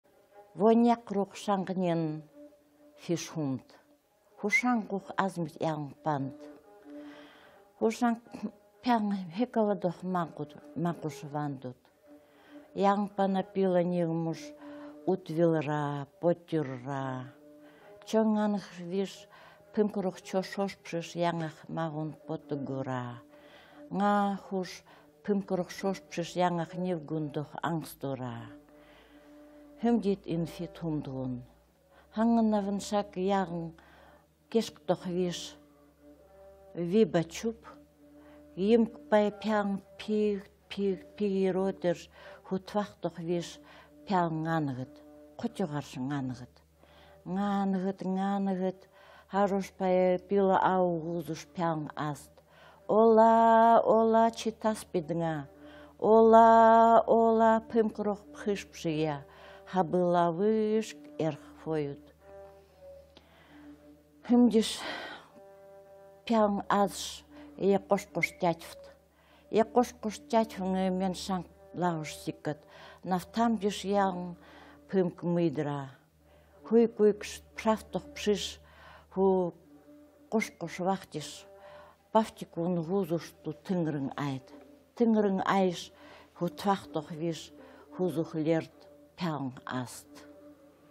рассказывает легенду о том, как появился музыкальный инструмент "тынрын" (нивхская однострунная смычковая пиколютня).